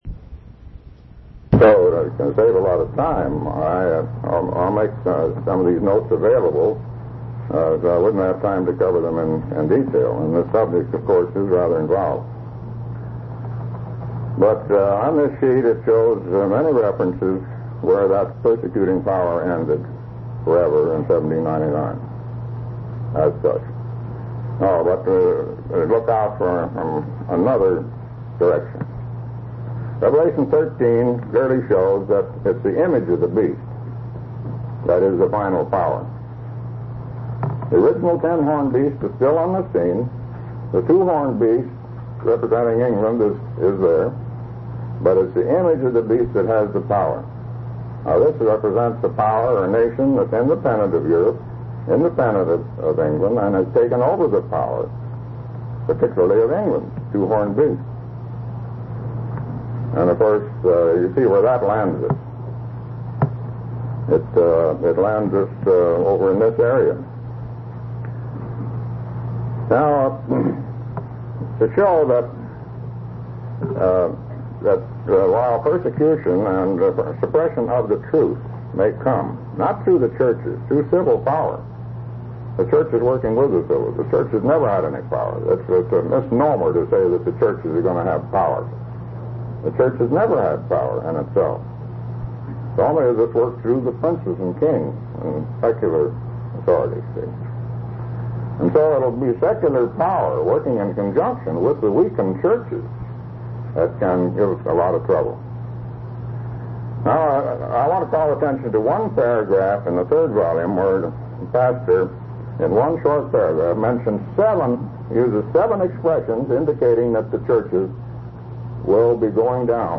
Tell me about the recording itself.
Waco, TX